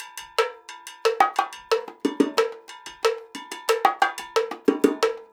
90 BONGO 8.wav